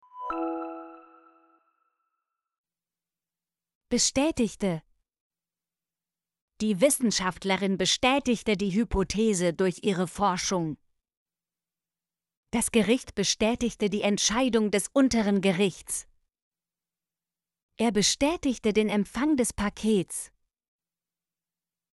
bestätigte - Example Sentences & Pronunciation, German Frequency List